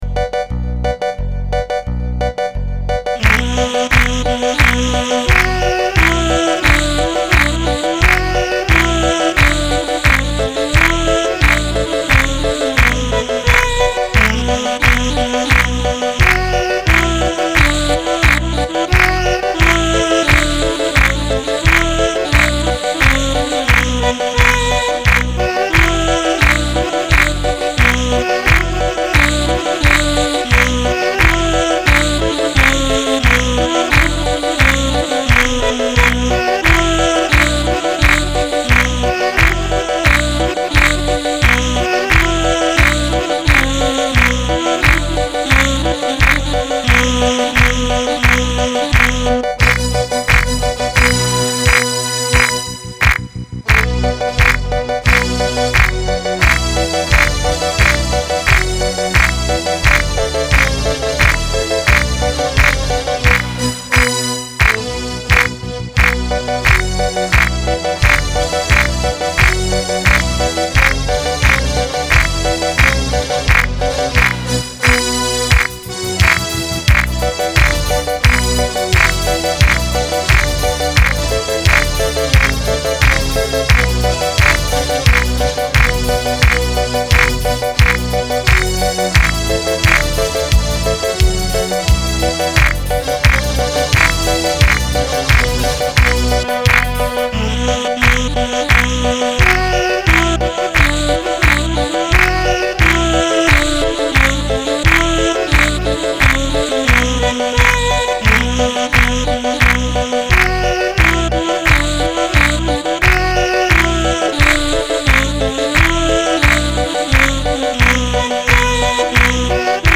مولودی شیرازی